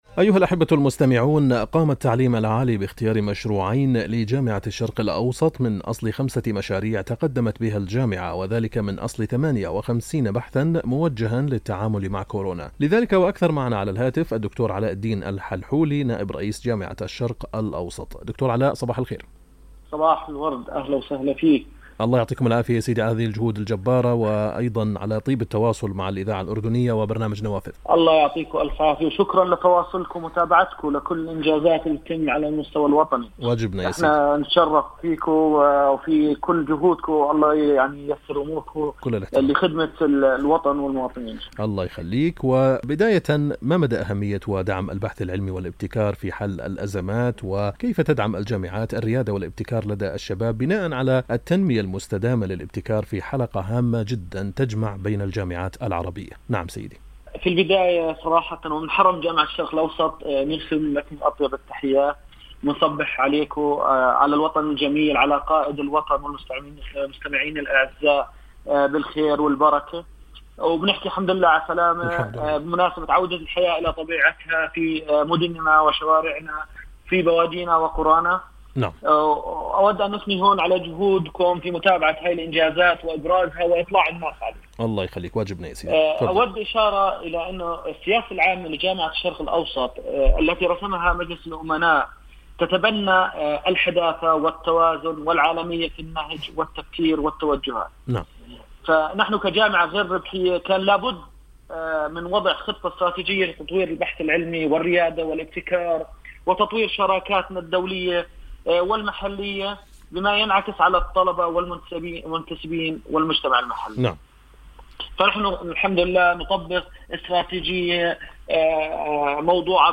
خلال استضافته على برنامج “نوافذ”، والذي يبث عبر أثير الإذاعة الأردنية